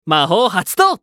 男性
☆★☆★システム音声☆★☆★